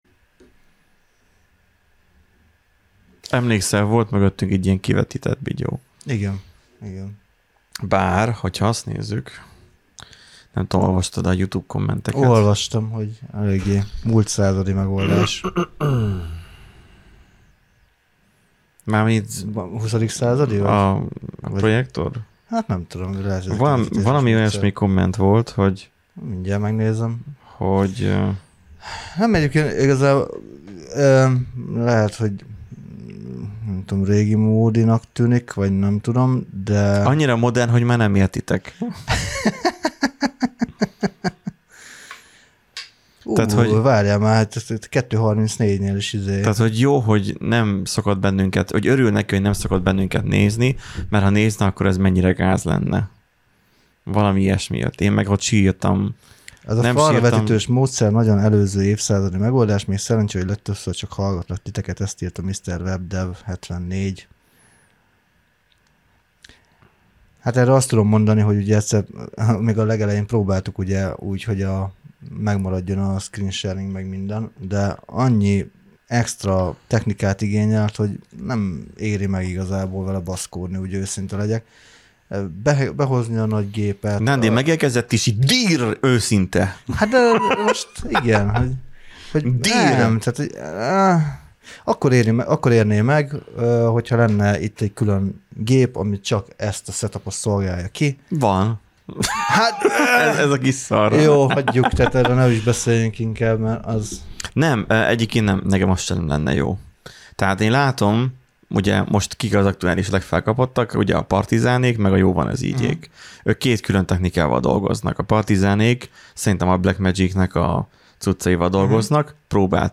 Néhány borsodi programozó srác leül hétről hétre és elmondják véleményüket a világban történtekről. A kontent nem mindig családbarát , de annál inkább őszinte, ironikus, szarkasztikus és szókimondó.